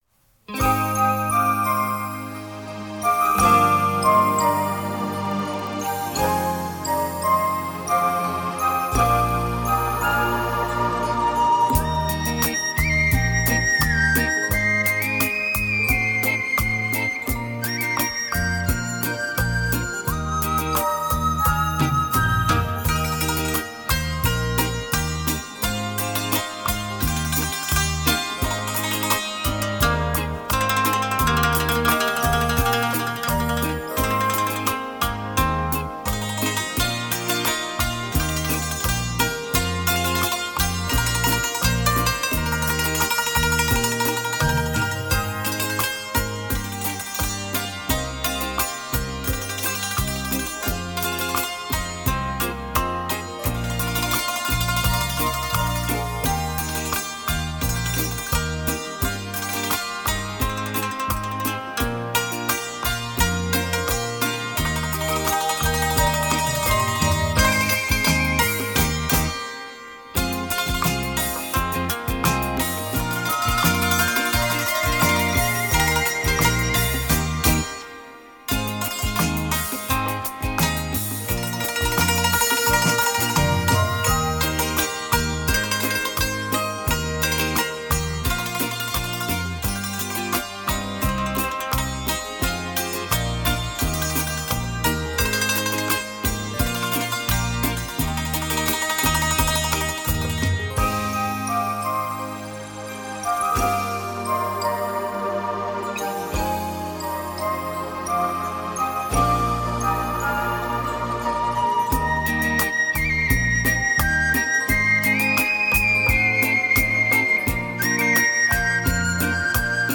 POPULAR MUSIC
整碟电子风格全程演绎